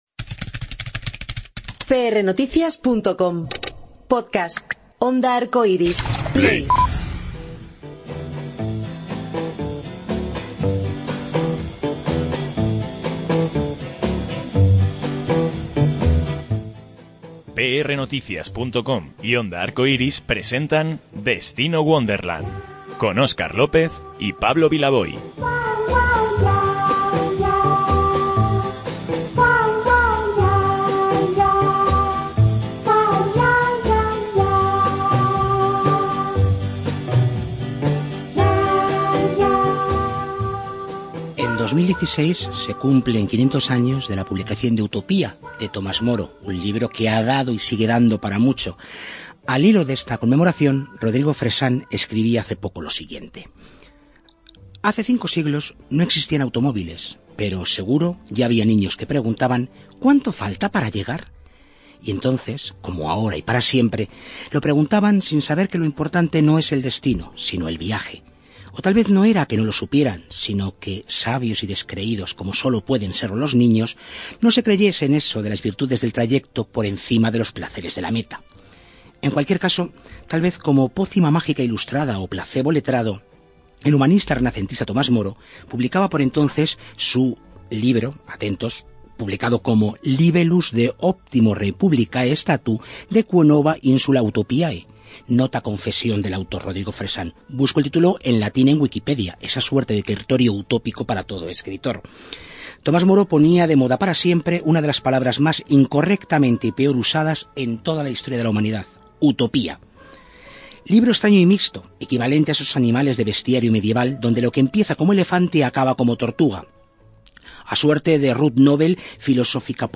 Lo entrevistamos en Destino Wonderland.